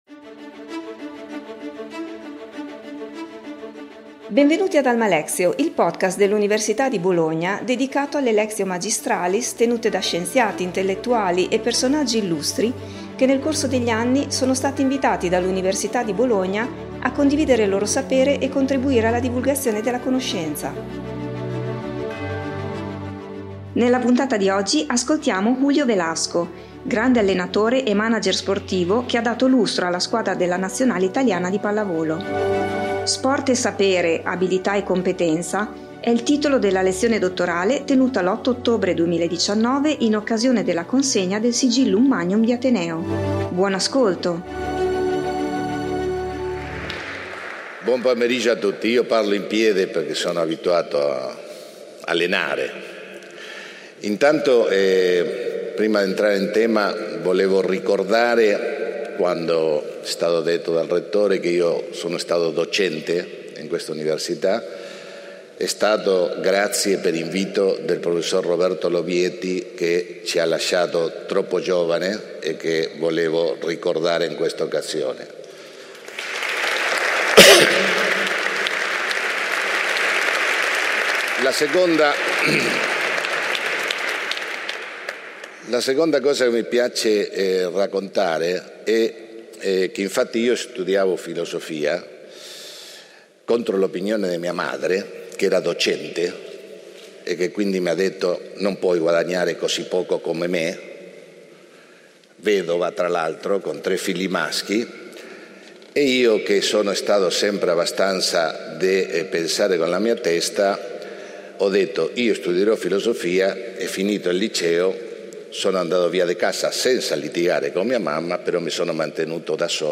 Julio Velasco, grande allenatore e manager sportivo che ha dato lustro alla squadra della Nazionale Italiana di pallavolo, ha tenuto la sua Lectio Magistralis l’8 ottobre 2019 in occasione della consegna del Sigillum Magnum di Ateneo.